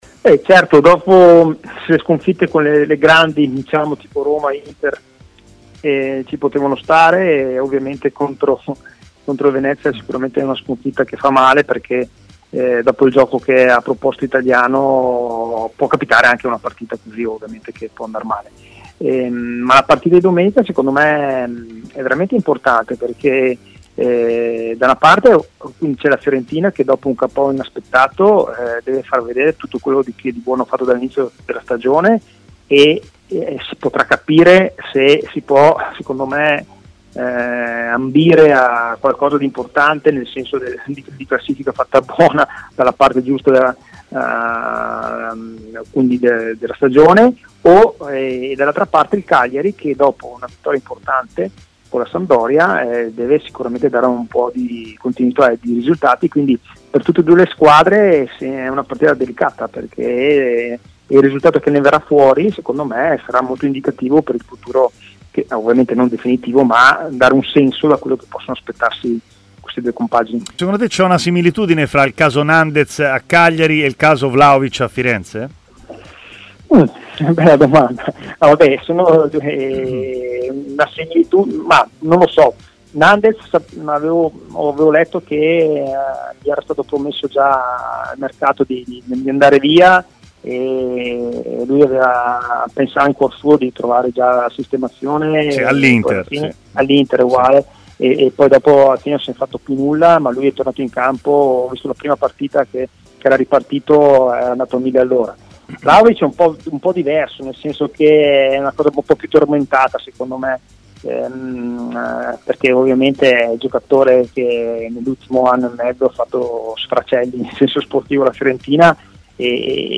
L'ex centrocampista viola Mauro Bressan ha parlato di Fiorentina e del caso Vlahovic a Stadio Aperto su TMW Radio.